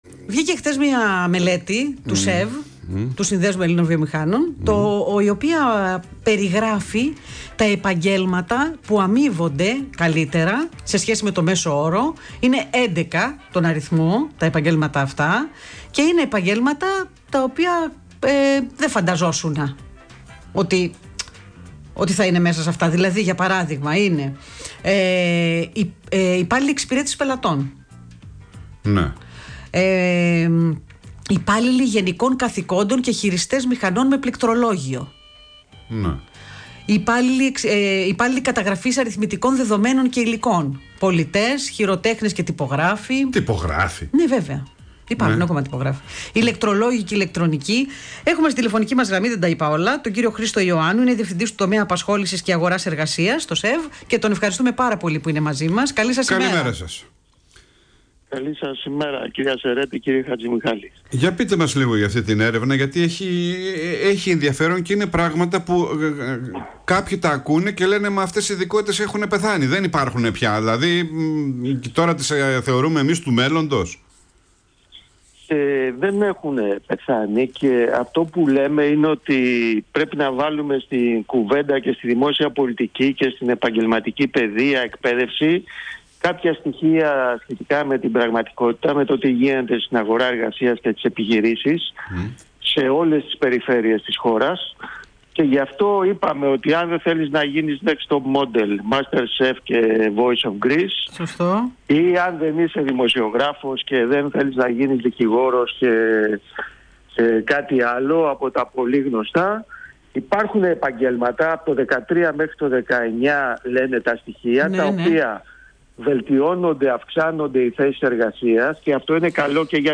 Συνέντευξη
στον Ρ/Σ Πρώτο Πρόγραμμα της ΕΡΤ, στην εκπομπή “Πρωινή παρέα”